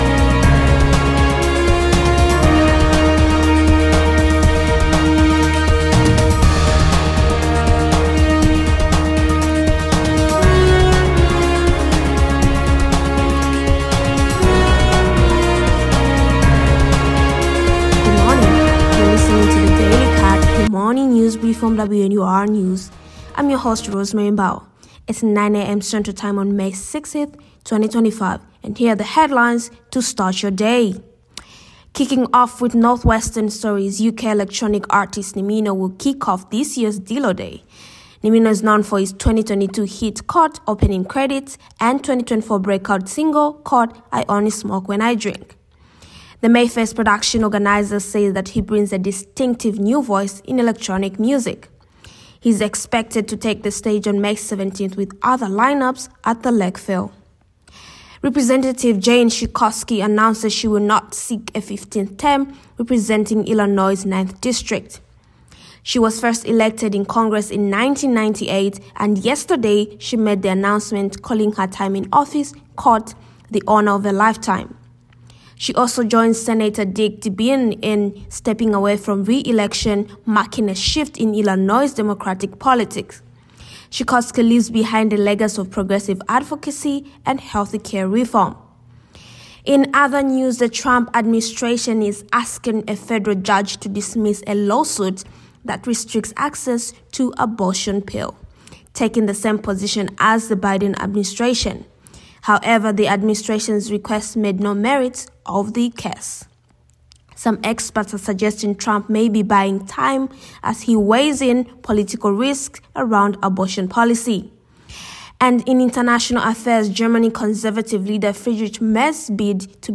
WNUR News Daily Briefing – May 6, 2025 DILLO DAY, JAN SCHAKOWSKY, ABORTION PILL, GERMAN ELECTIONS WNUR News broadcasts live at 6 pm CST on Mondays, Wednesdays, and Fridays on WNUR 89.3 FM.